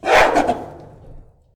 sounds_gorilla_roar.ogg